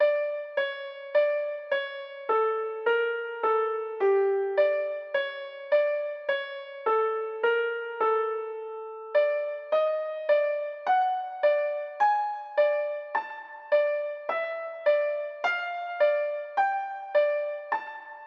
Tag: 105 bpm Rap Loops Piano Loops 3.08 MB wav Key : Unknown